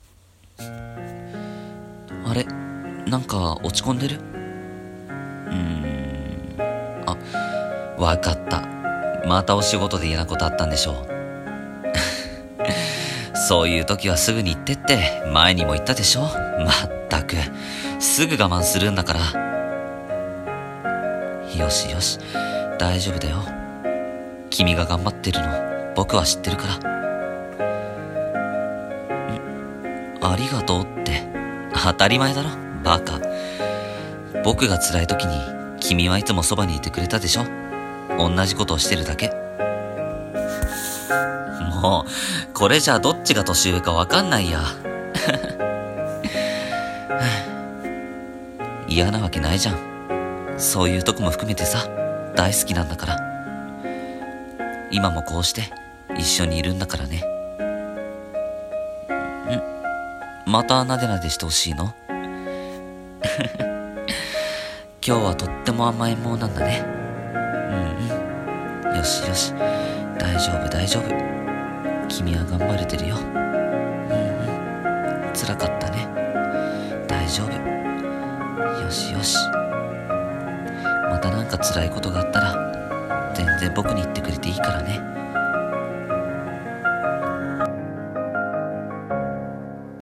声劇[大丈夫]【１人声劇】